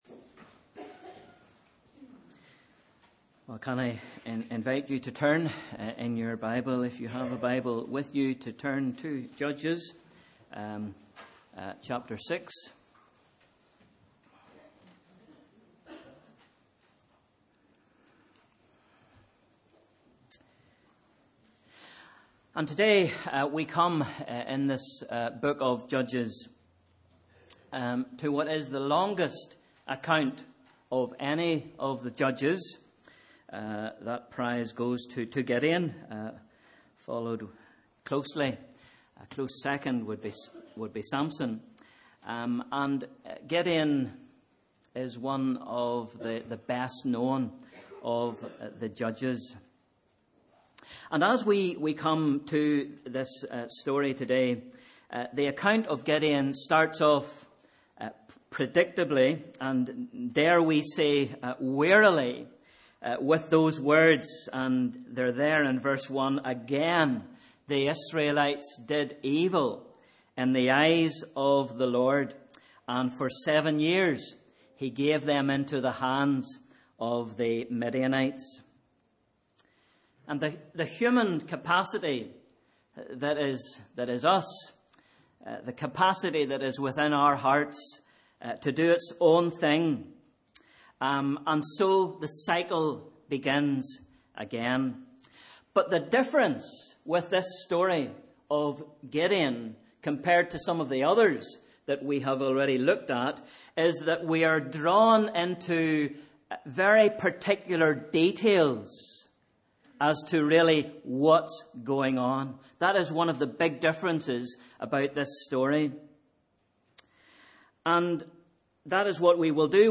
Sunday 22nd April 2018 – Morning Service